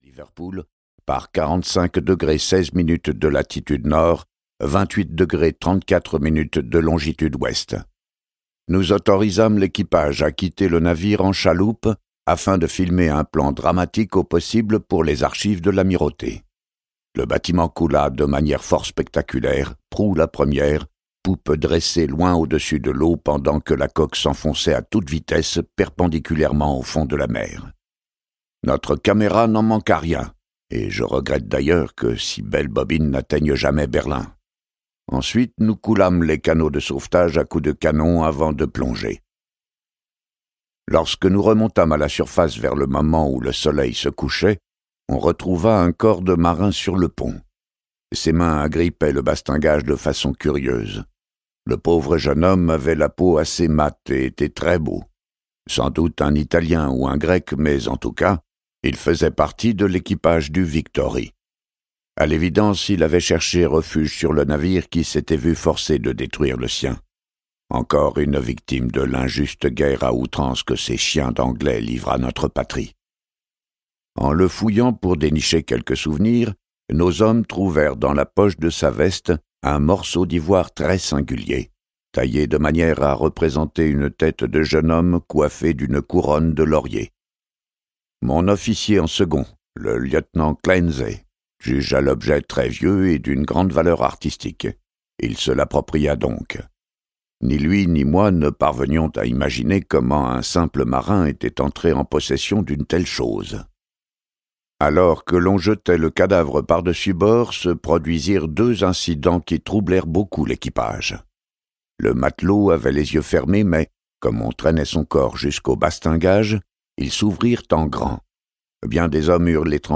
Le mythe de Cthulhu n'a jamais été aussi réel…Ce livre audio est interprété par une voix humaine, dans le respect des engagements d'Hardigan.